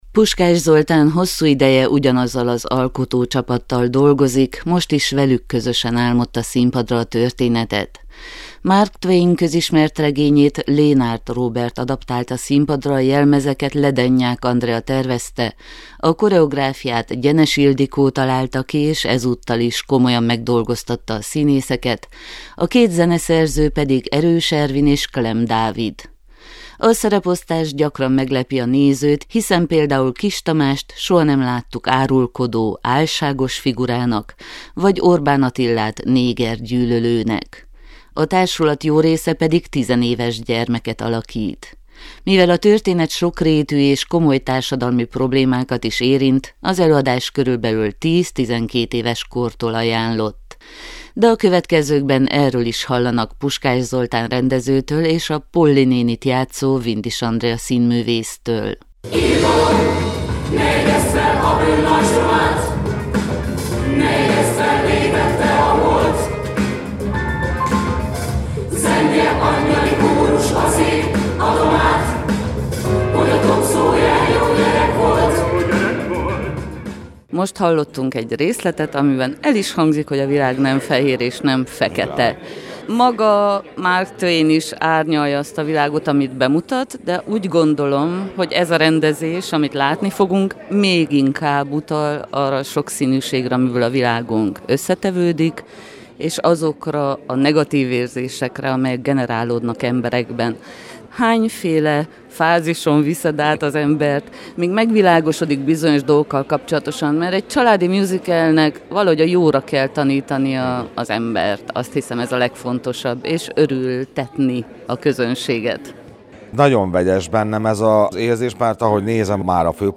A pénteki bemutató előtt az alkotók két jelenetet megmutattak a sajtónak - mi is ott voltunk.